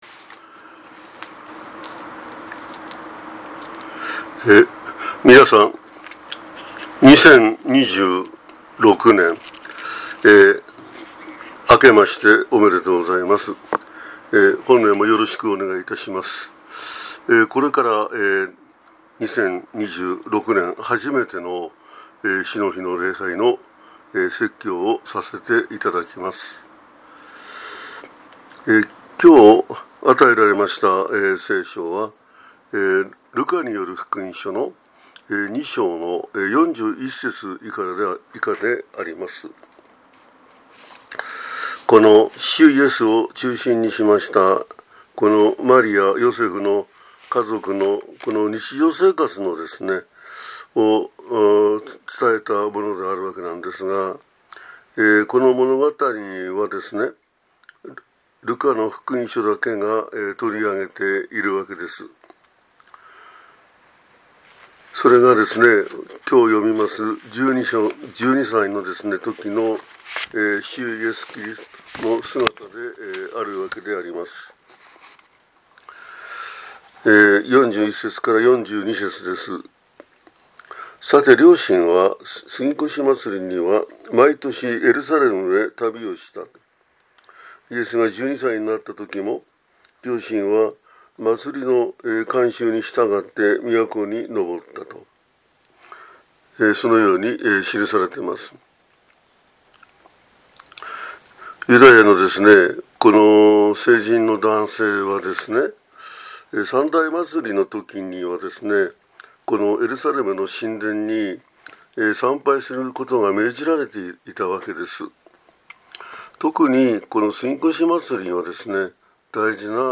2026年1月4日（降誕節第2主日）